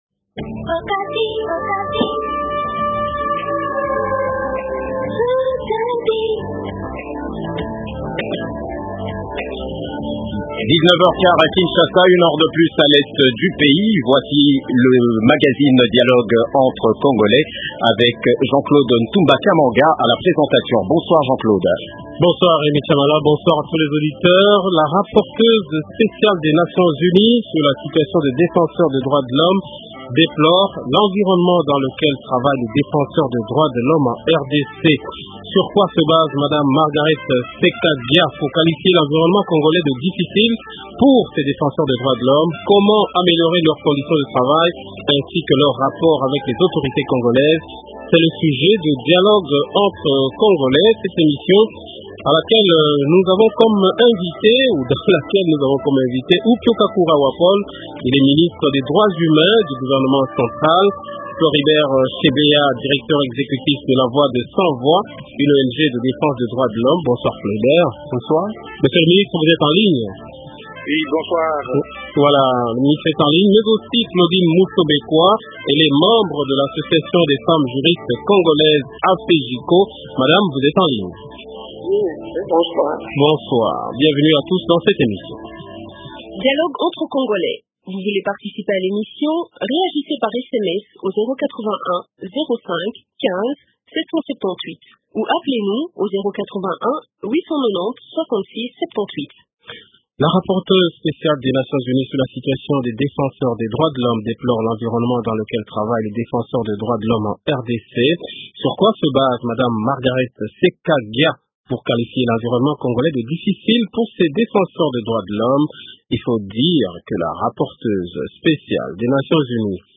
- Comment améliorer leur conditions de travail ainsi que leurs rapports avec les autorités congolais ? Invité -Upio Kakura wapol, Ministre des droits humains du gouvernement central. -Floribert Chebeya, Directeur exécutif de la Voix de sans voix, une Ong de défense des droits de l’Homme.